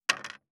585魚切る,肉切りナイフ,
効果音厨房/台所/レストラン/kitchen食器食材